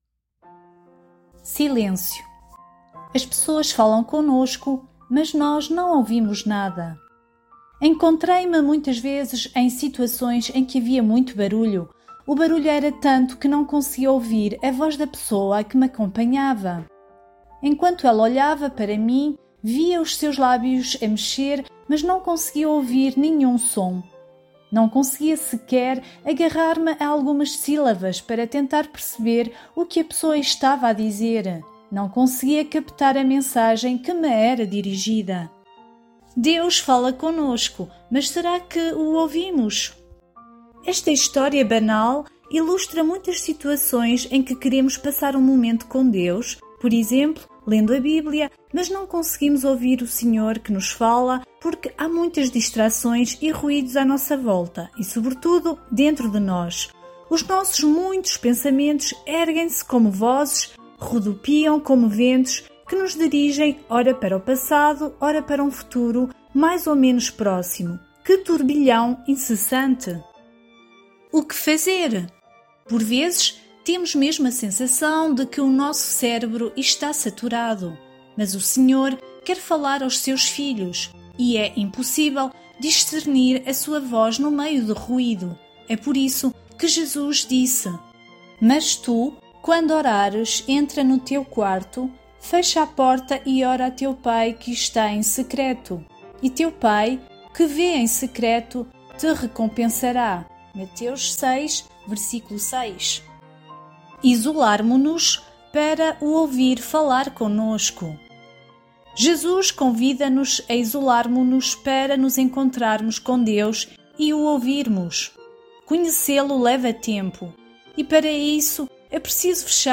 audio com música